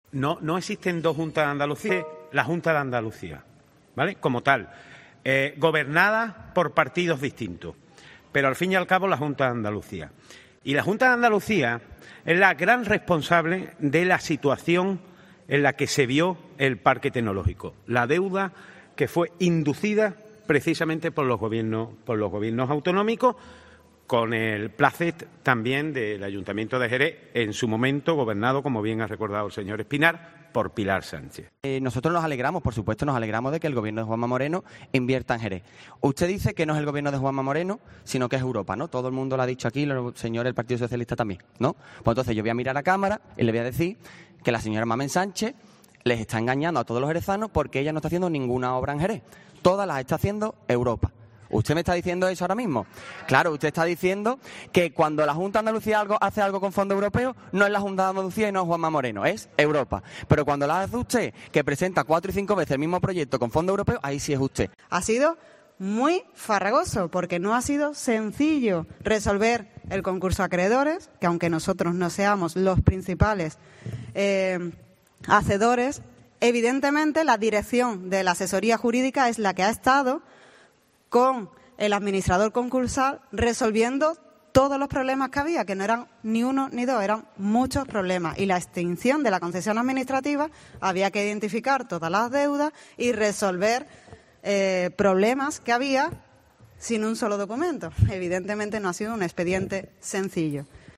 Escucha aquí a los concejales Raúl Ruiz-Berdejo (Adelante Jerez), Jaime Espinar (PP) y Laura Álvarez (PSOE)